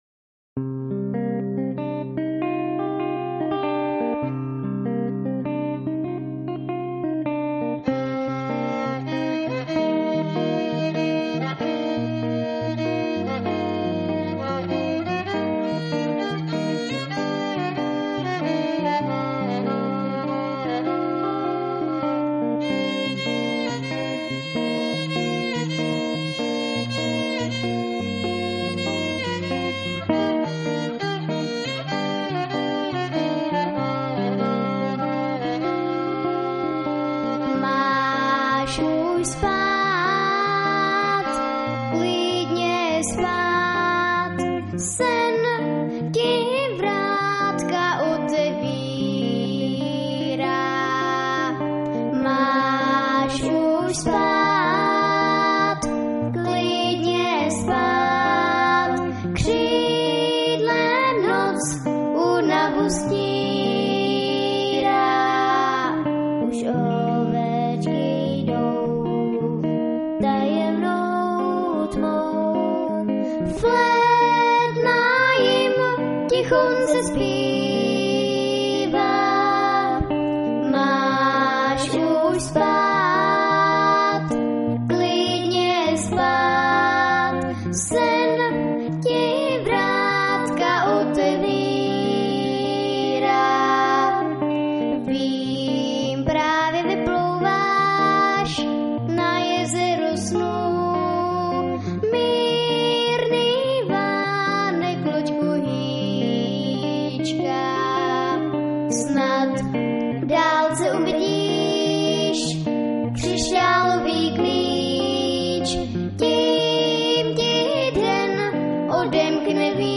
V loňském roce na táborské Bambiriádě 2006 opět vystoupili vítězové Dětské porty z Českého Krumlova - duo